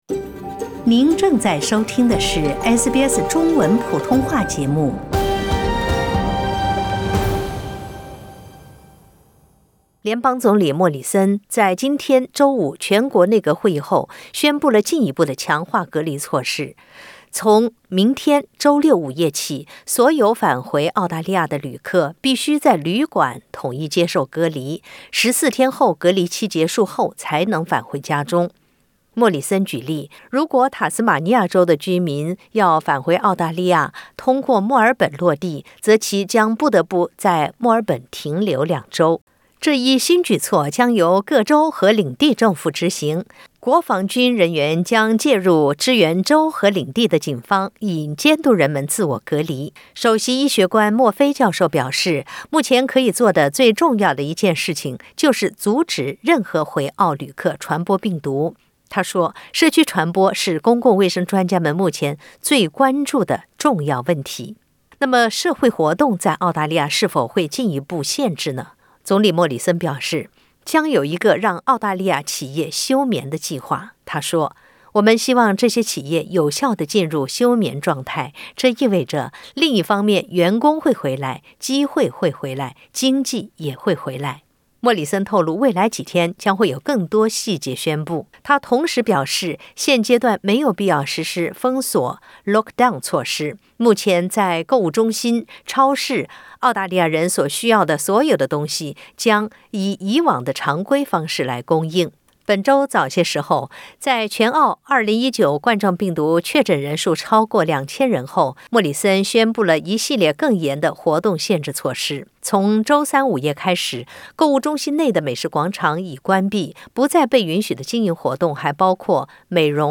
点击上方图片收听录音报道。